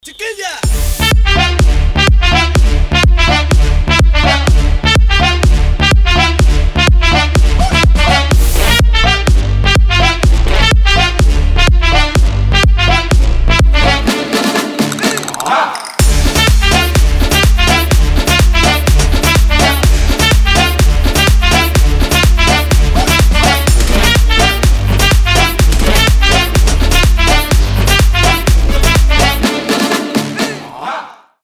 • Качество: 320, Stereo
ритмичные
зажигательные
веселые
electro house
кастаньеты